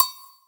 Percussion #03.wav